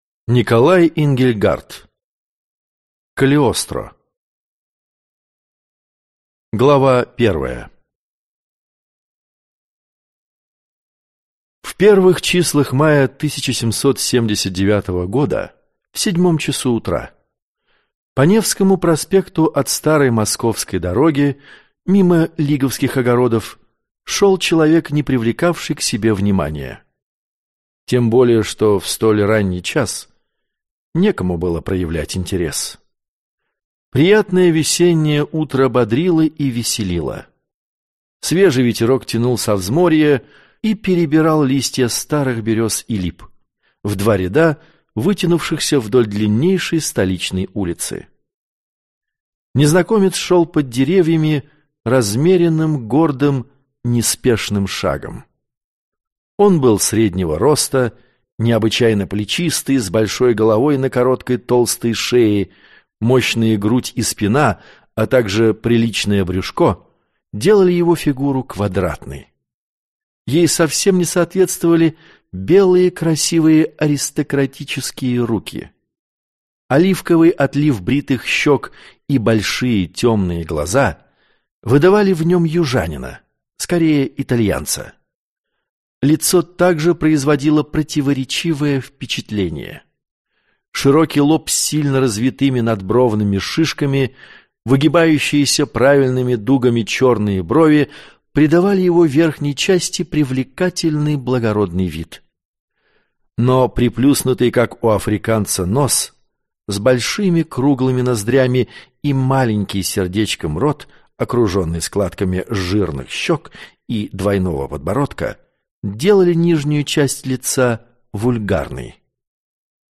Аудиокнига Калиостро | Библиотека аудиокниг